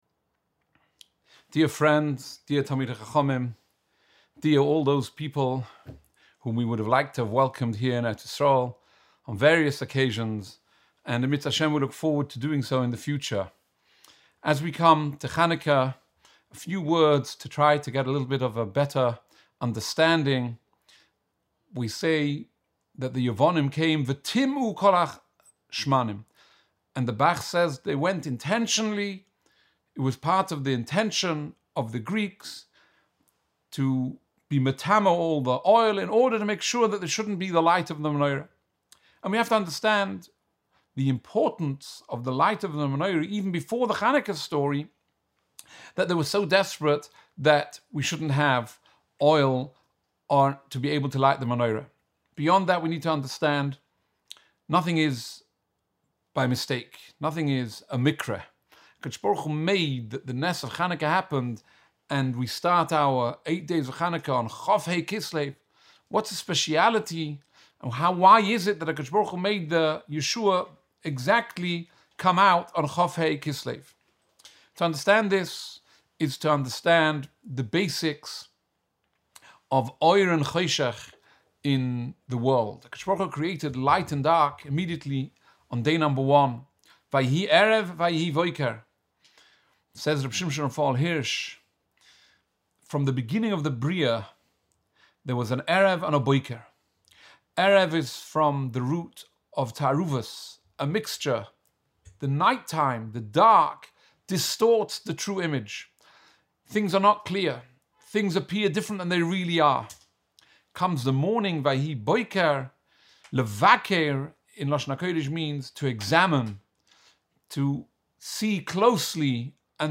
Shiurim